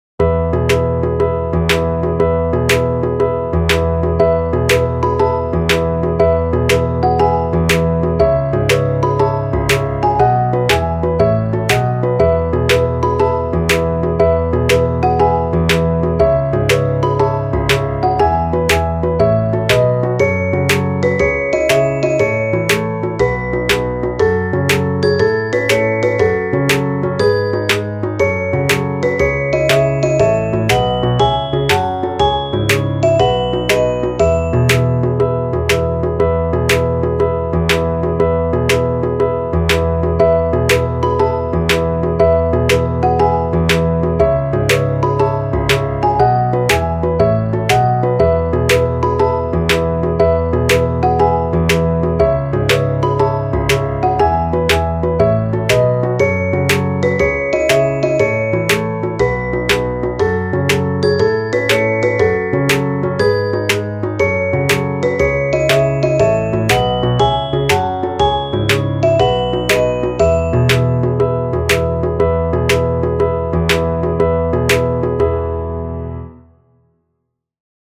少なくシンプルな音で、日常感、まったり感を表現しています。
Vlogやペット等、日常でのまったりシーンを表現したい場面にぴったりなBGMです。
マリンバ
ほのぼの リラックス 楽しい 穏やか